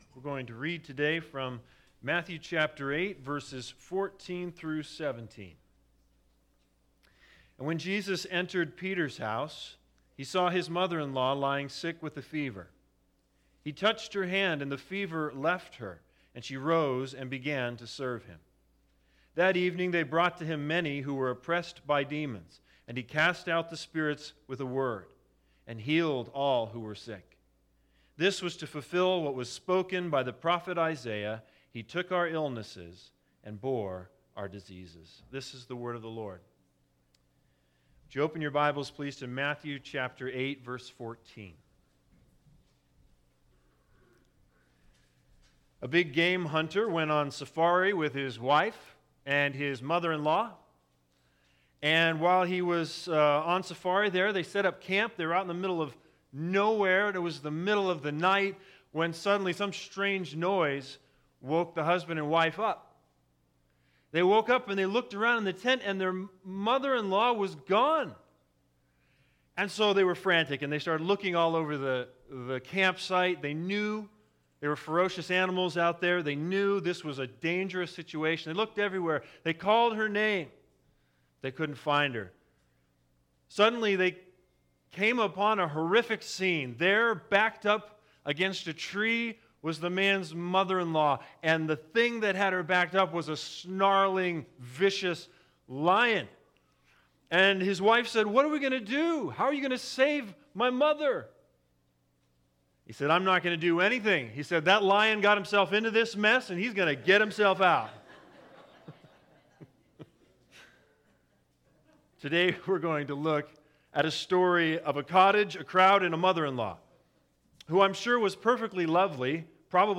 Matthew 8:14-17 Service Type: Sunday Sermons The Big Idea